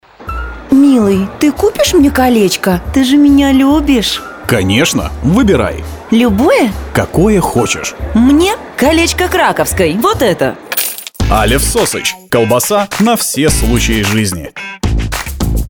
Муж, Рекламный ролик/Средний
Профессиональная студия звукозаписи со всем сопутствующим оборудованием.